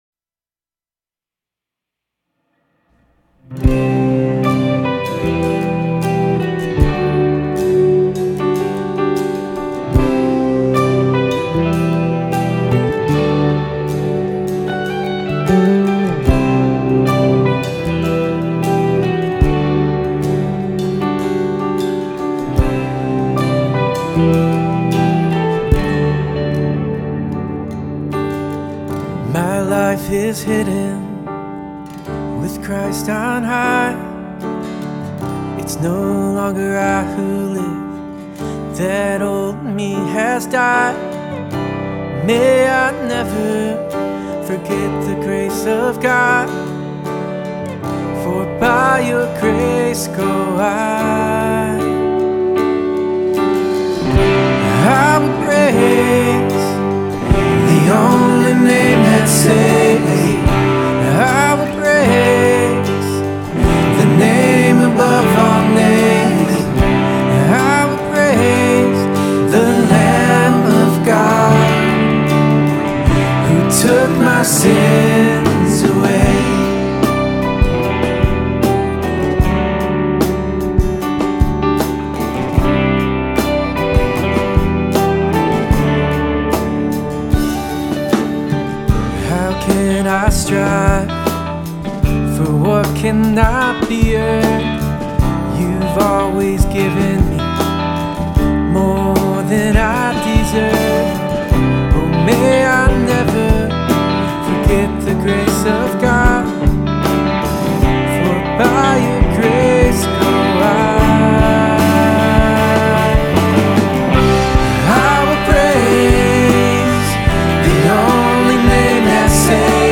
We have 4-5 worship songs we've been working on the last year or so. This is the first go at an arrangement and tracking. It has not been mixed, eq'd or mastered since it's still in a raw form.